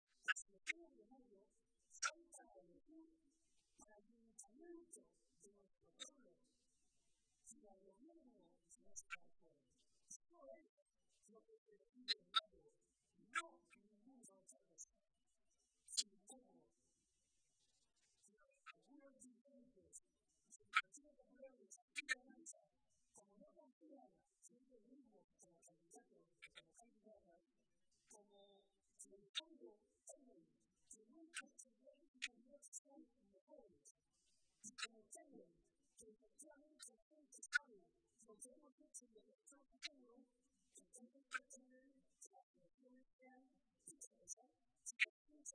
El presidente José María Barreda asistía este domingo a la tradicional comida de Navidad del PSOE de Toledo, en la que participaron unos 1.600 militantes y simpatizantes de toda la provincia.